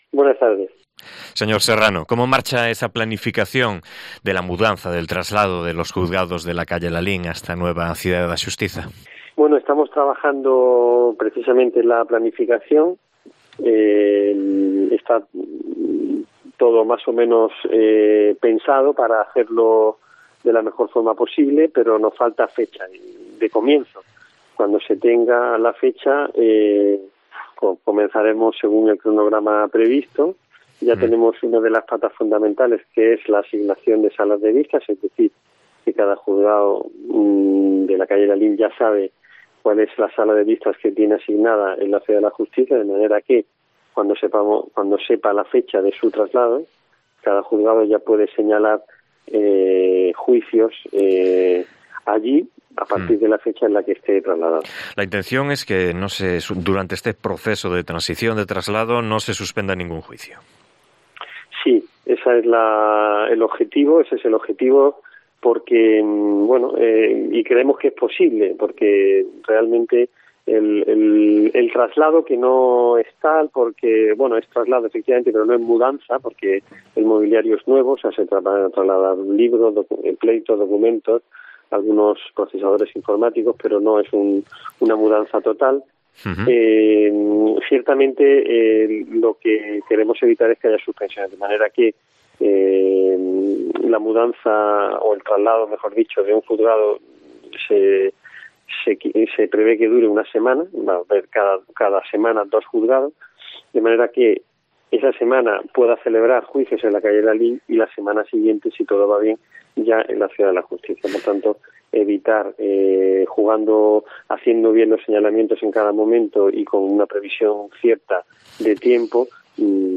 Entrevista con German Serrano, decano de los jueces de Vigo